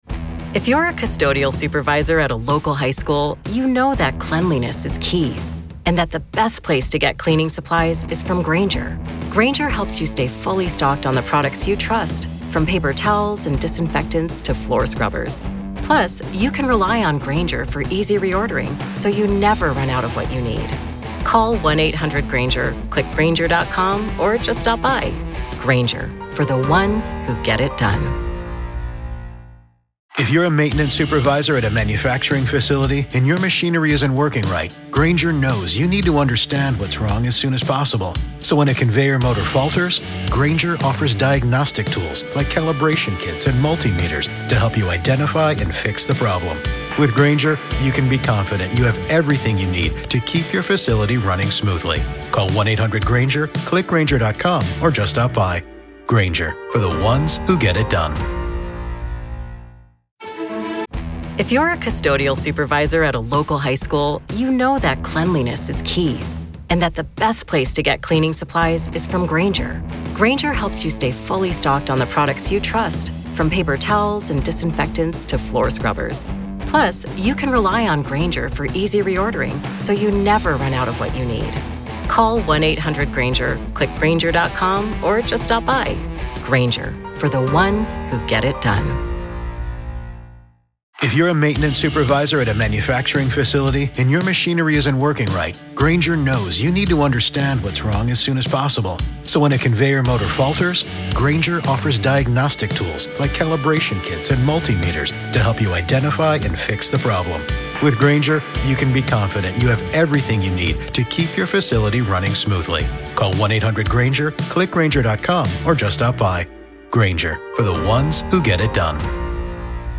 COURT AUDIO: Bryan Kohberger Court Hearings Focus on Evidence Suppression in Idaho Student Murders -PART 3